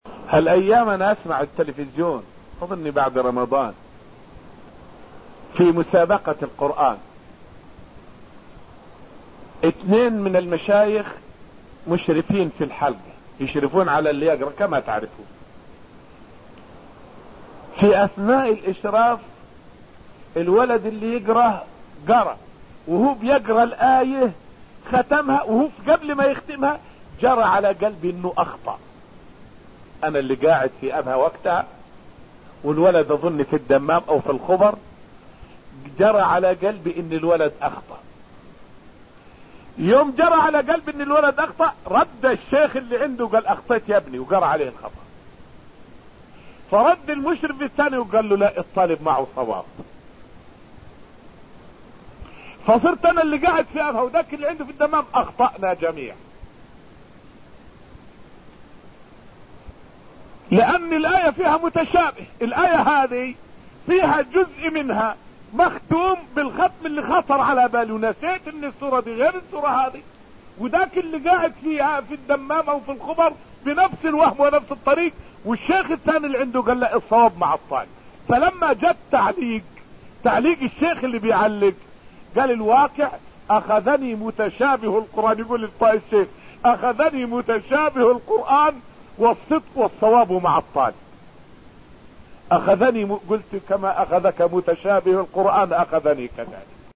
فائدة من الدرس الأول من دروس تفسير سورة الرحمن والتي ألقيت في المسجد النبوي الشريف حول تواضع الشيخ بذكره خطأ وقع له بسبب متشابه القرآن أثناء متابعة مسابقة على التلفاز.